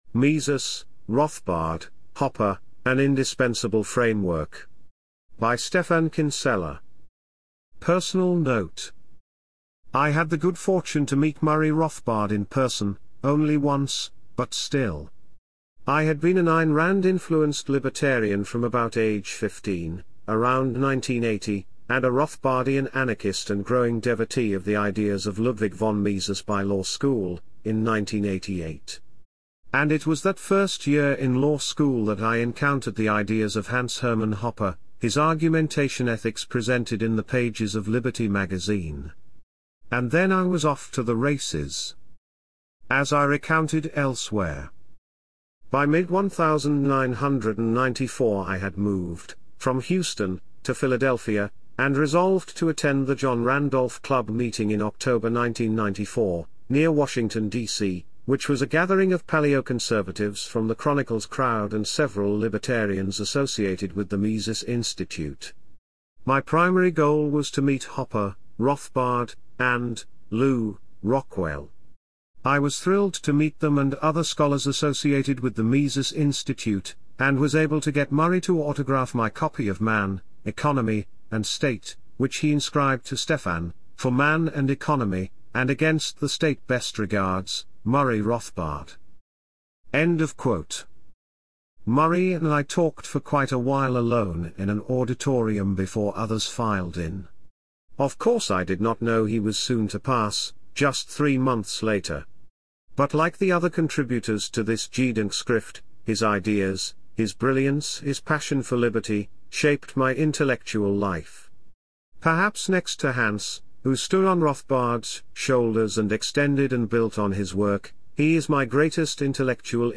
AI-assisted audio narration of the main chapters of Rothbard at 100: A Tribute and Assessment (Papinian Press and The Saif House, 2026) is available at this PFS Youtube Playlist; the mp3 files may also be downloaded in this zip file.